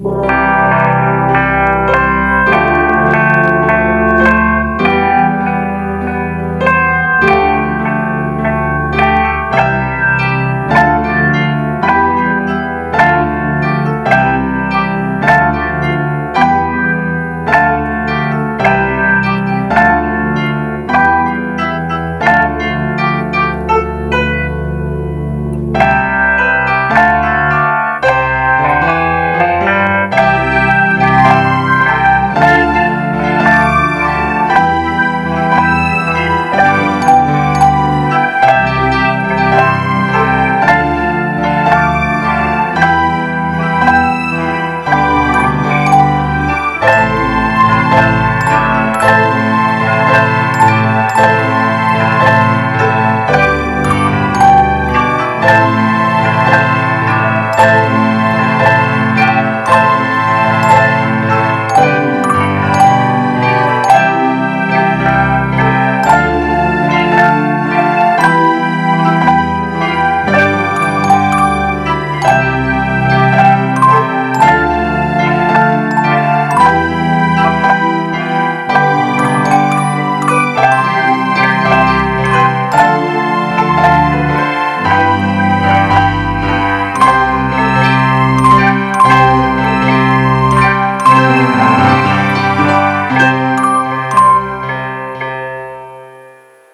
Fashionista Girl Melody Mix